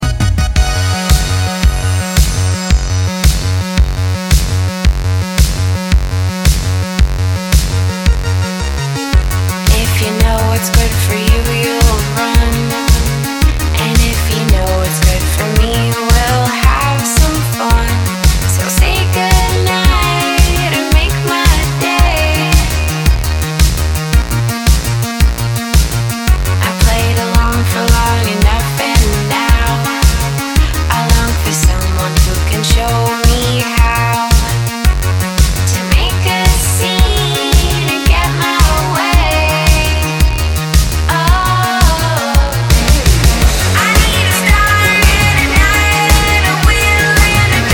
electronic pop
synth-charged pop music